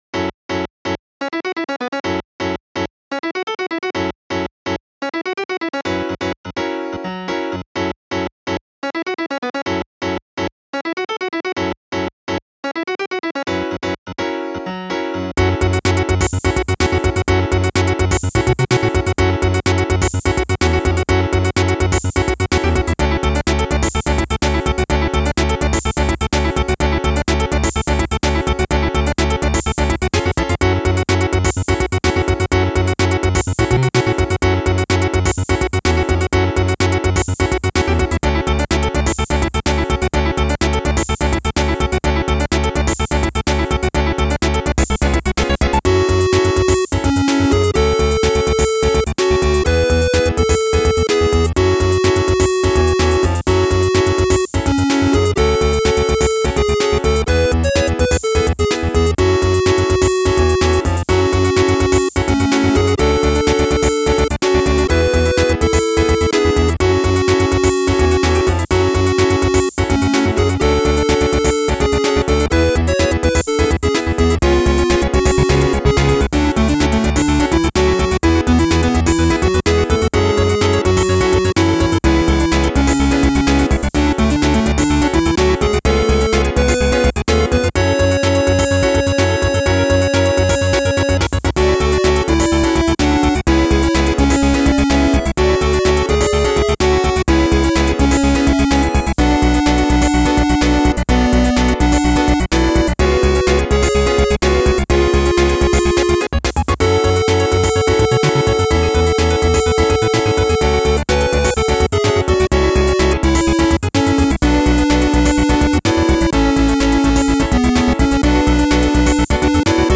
曲名はあまりにも謎めいてるけど、とりあえずピアノとファミコンが主役なのは多分理解できるはず。